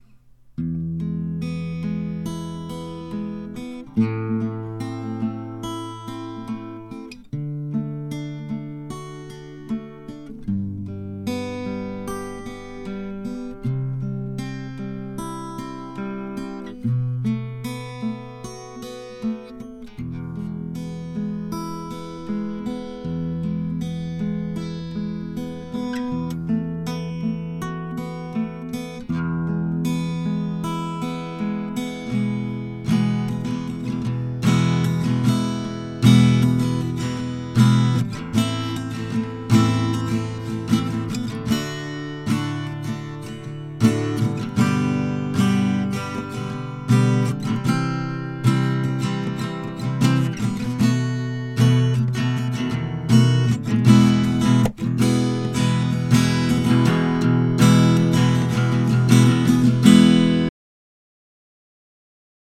Last week I recorded the guitar part, attached to this thread.
Last week I recorded the guitar part, attached to this thread. Dry recording, no effects
All was recorded in a small room (around 2x3m) with ten handmade rockwool absorption panels to record it as dry as I could go. I think the recorded guitar part (and also the voices) sound very thin. muffled in the low end, no nice mids, and harsh mid-highs.